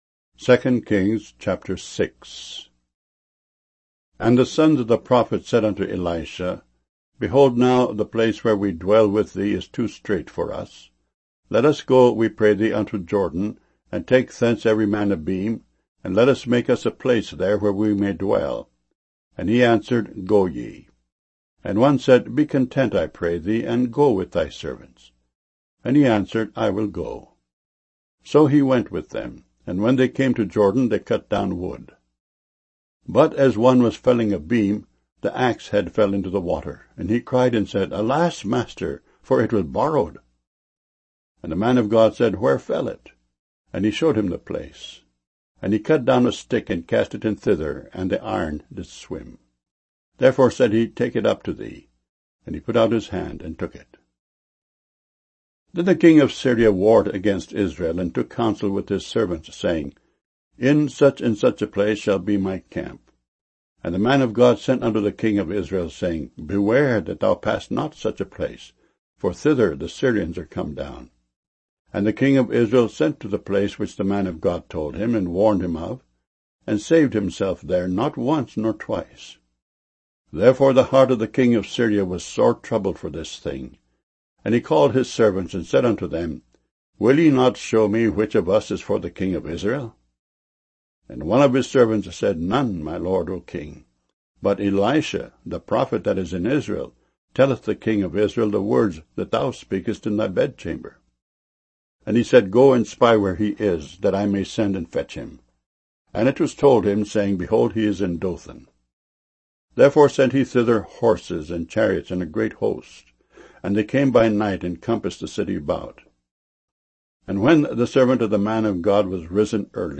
MP3 files mono 32 kbs small direct from wav files